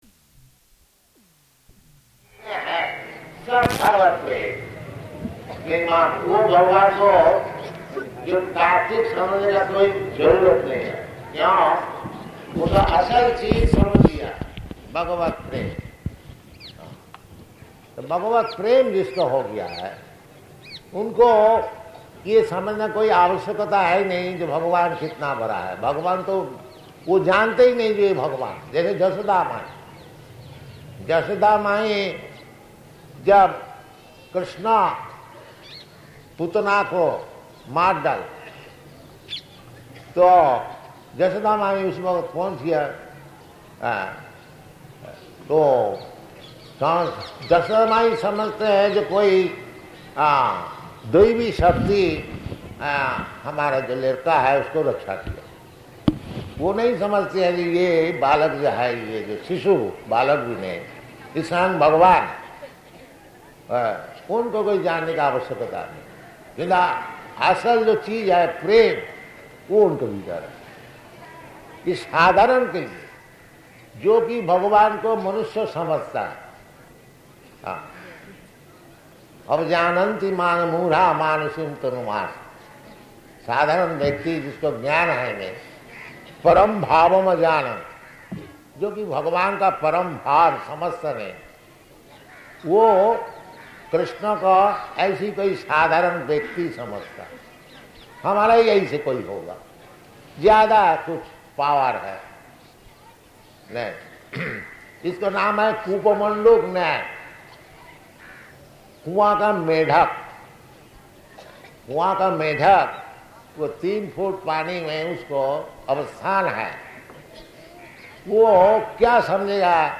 Lecture in Hindi
Type: Lectures and Addresses
Location: Jaipur
Initiation Lecture [partially recorded]